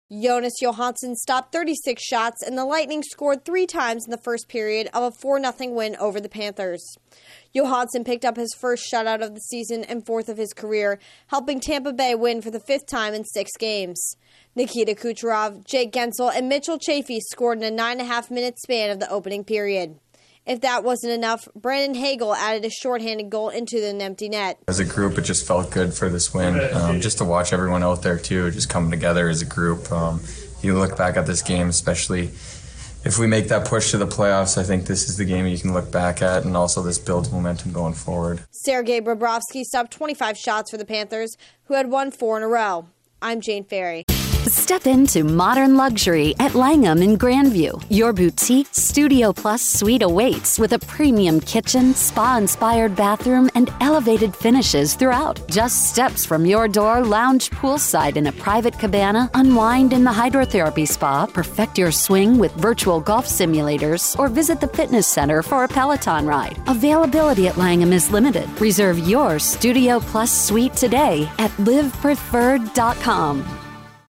The Lightning get a split of their two-game set with the Panthers. Correspondent